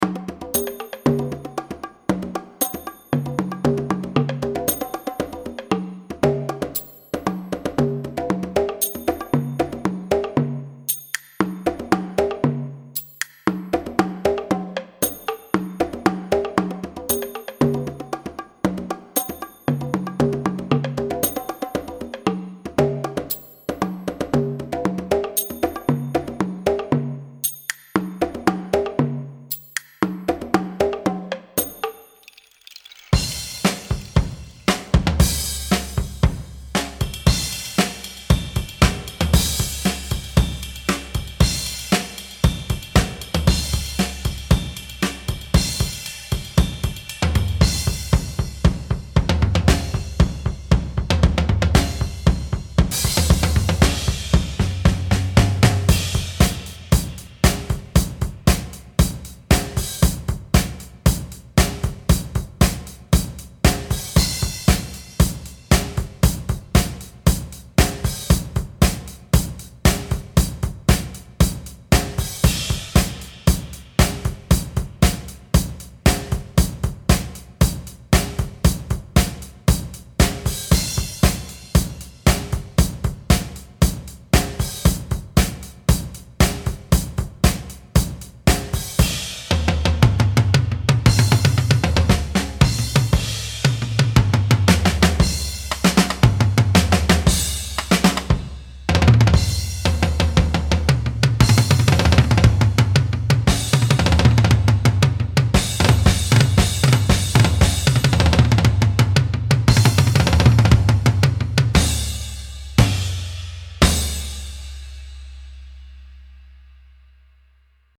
Blushing, I giggled, "I play mostly hand percussion, but my new dad has been teaching me to play a drum kit too."
I concentrated on the bongos and congas
started playing at about one-hundred-sixteen beats per minute
After about sixteen measures I moved over to play the drum kit.
My little solo lasted about two minutes, but I noticed Dillon and Jonah with their jaws hanging open.